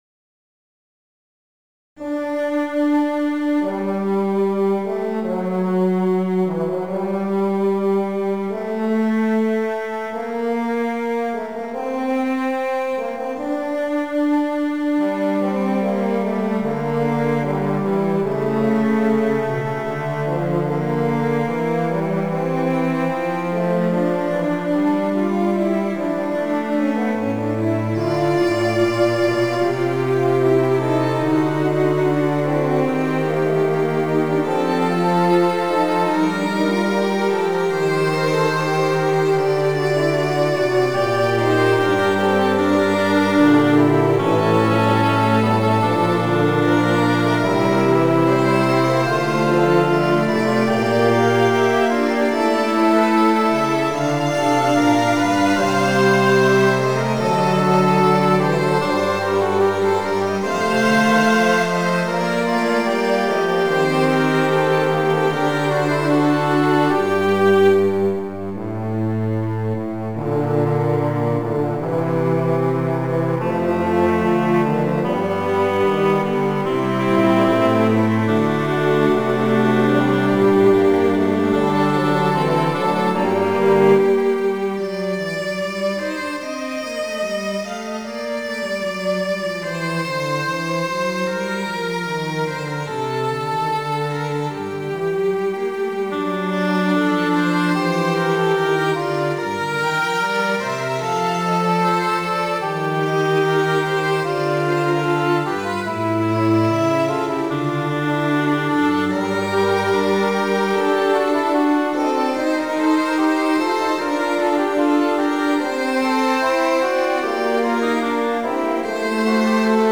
Midi Instrumental ensemble (Fl-Ob-Hn-Trb-Str)